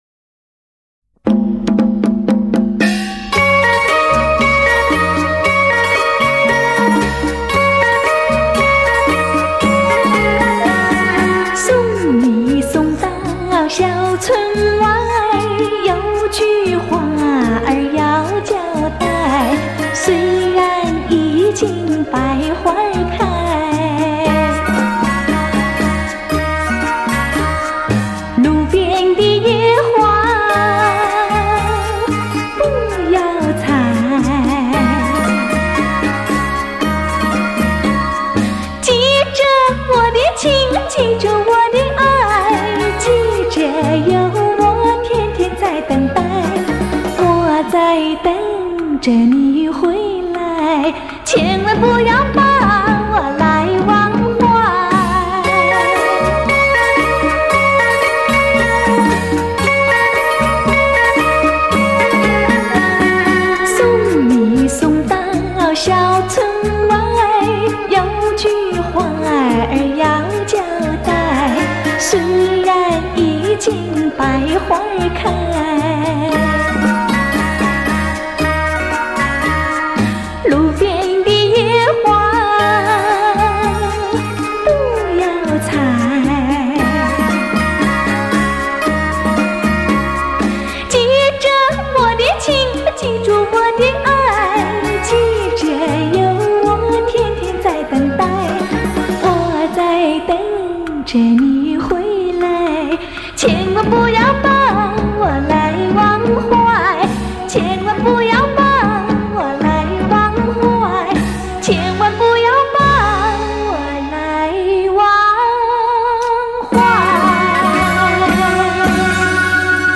聆觅丽影君情歌华倩韵 黑胶唱片原音回放
首度以高保真CD正式出版 原始母带经高新科技原音处理
既保留了黑胶唱片的暖和柔美 也展现了数码唱片的精确清晰
让那甜蜜柔美的声音再度飘进你的心窝 萦牵你的心弦